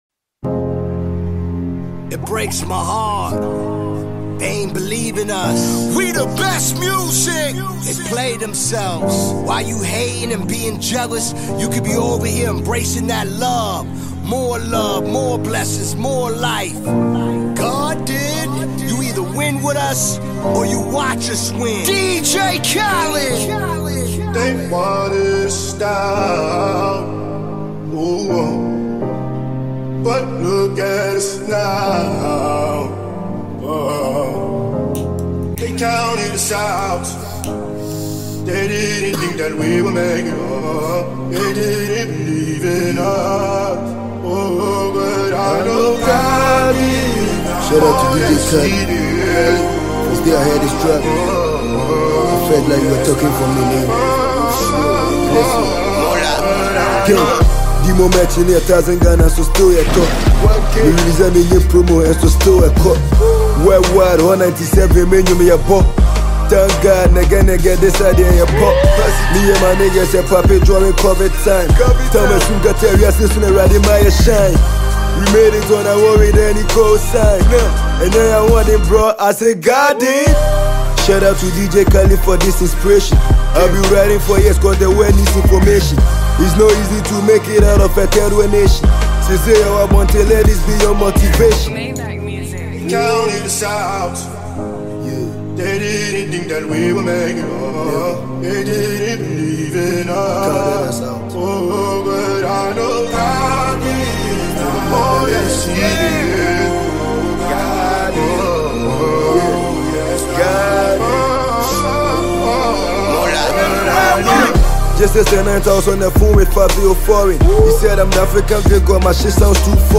Ghana Music
Ghanaian recording artiste & rapper